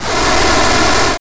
Monster3.wav